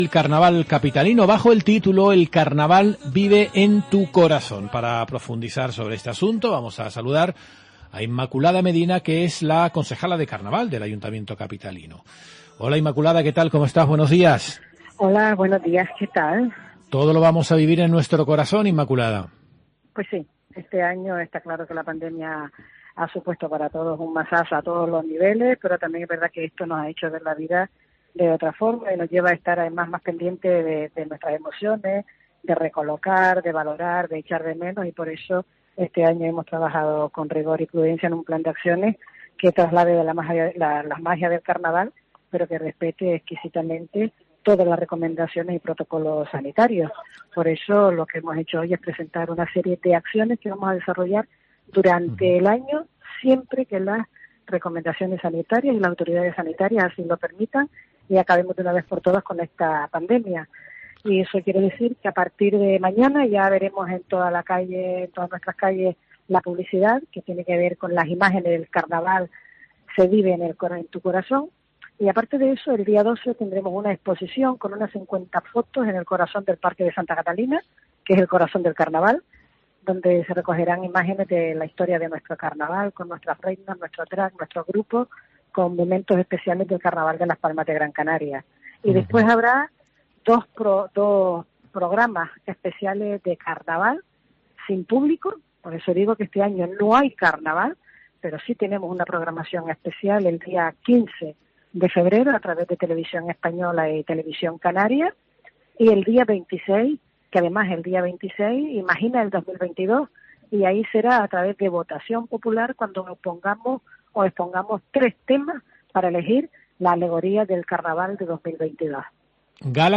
Entrevista Inmaculada Medina, concejala de Carnaval de Las Palmas de Gran Canaria